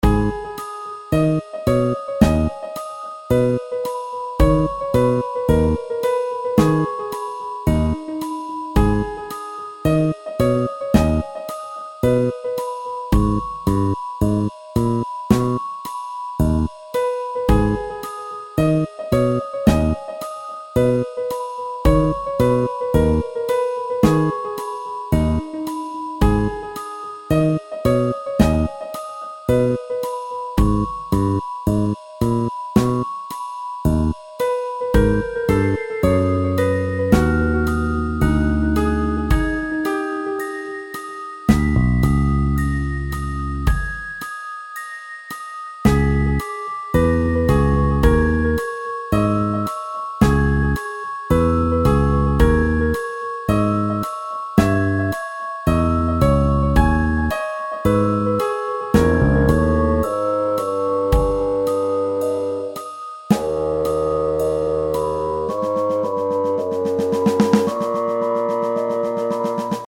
There 's always some "inside the house" music that's warm-sounding and soothing.
This track has a generous helping of the fairy sparkles that I'm trying to work in to the soundtrack (sort of a theme really).
Filed under: Instrumental | Comments (1)